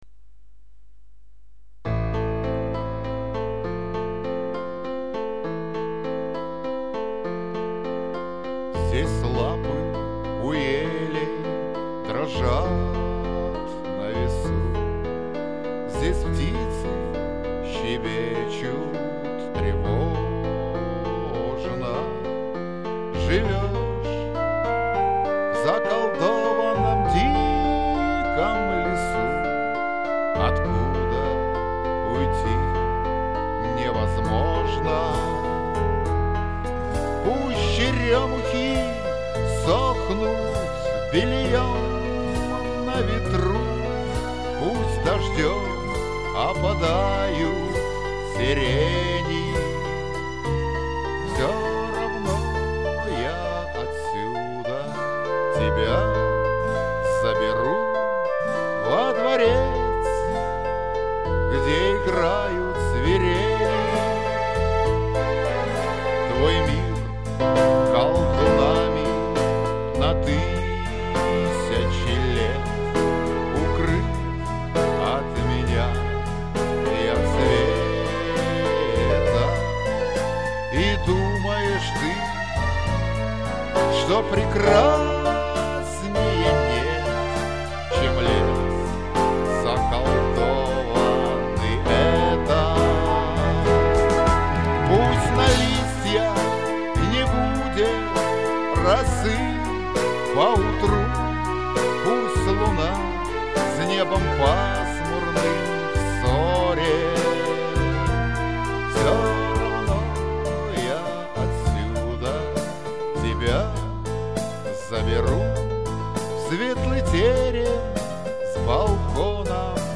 Лирическая